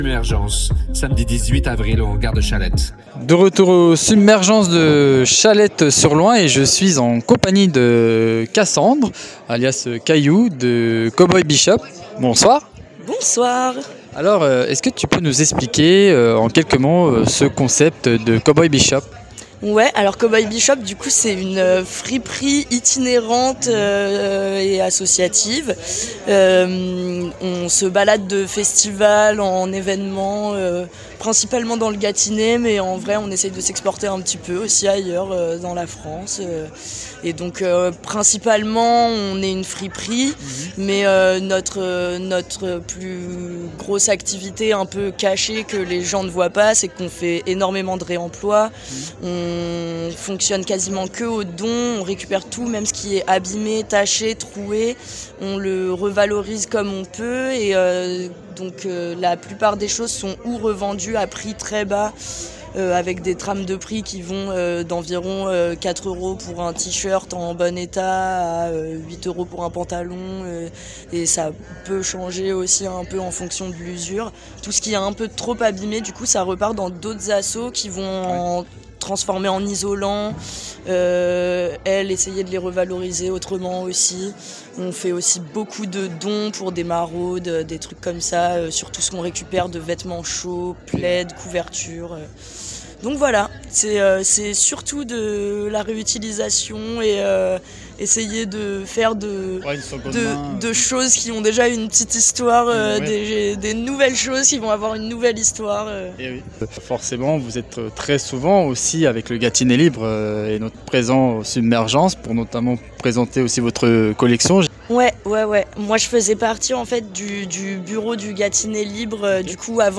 Dans cet épisode, on vous emmène au cœur du village associatif de Submergence, un événement porté par Gâtinais Libre au Hangar de Châlette-sur-Loing.